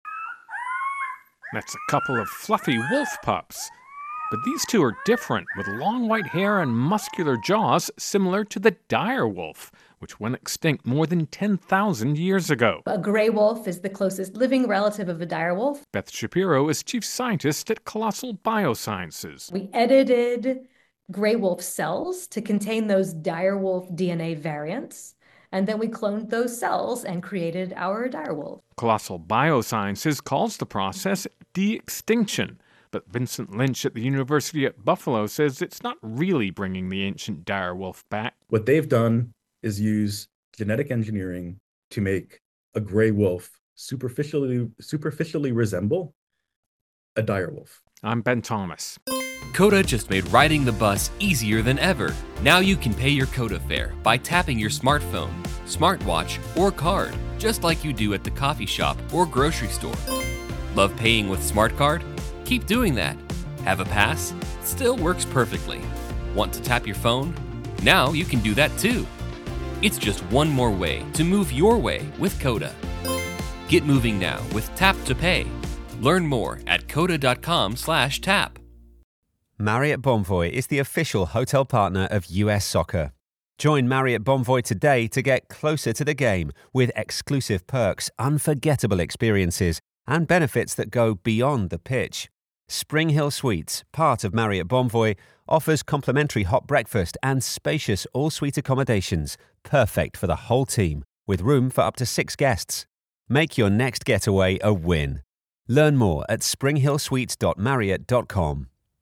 ((shorter version; opens with sound of wolf pups howling))